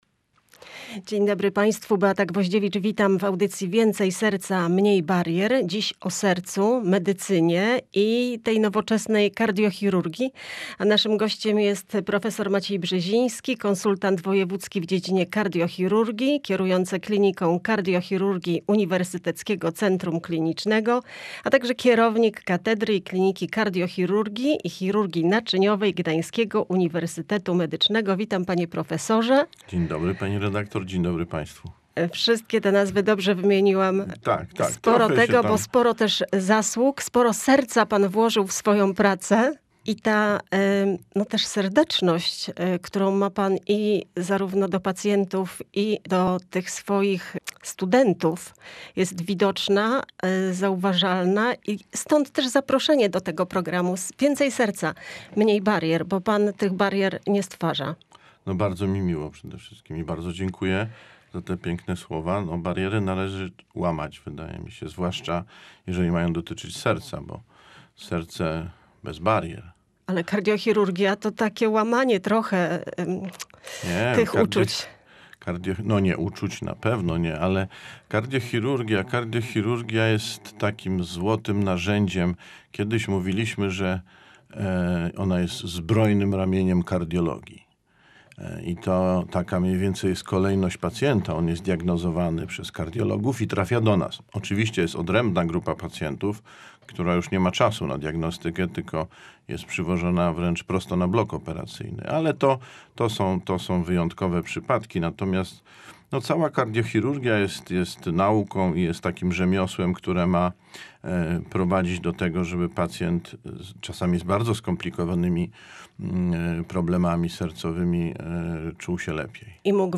Współpraca między szpitalami, uczelniami i ośrodkami badawczymi wpływa na poprawę jakości opieki Między innymi na ten temat rozmawialiśmy w audycji